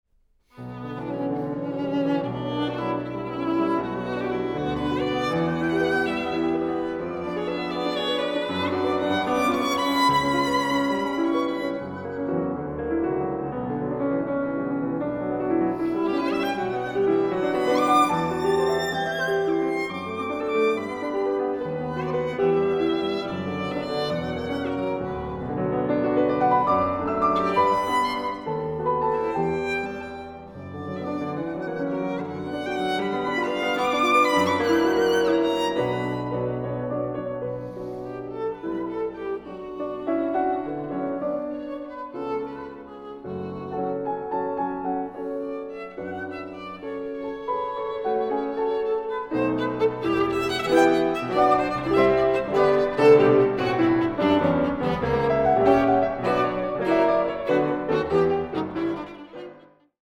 Sonaten für Klavier und Violine
Klavier
Violine